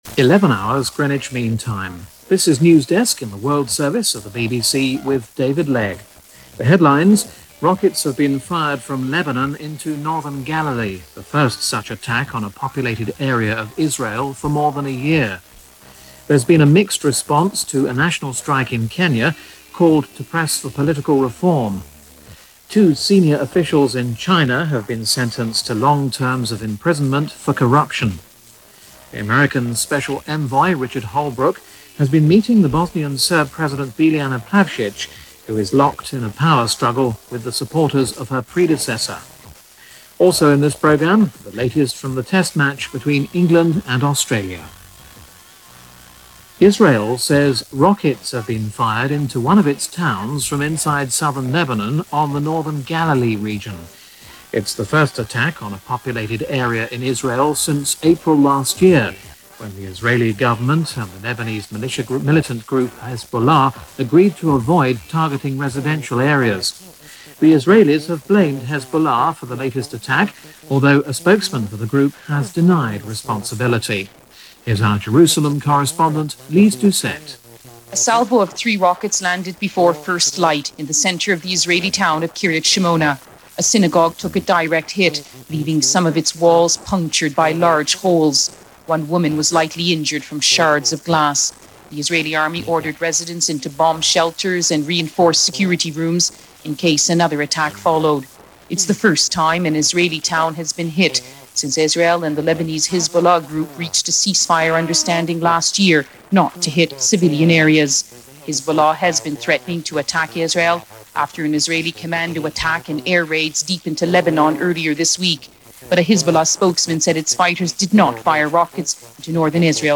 News in the world that sounds suspiciously familiar, despite being almost 30 years ago.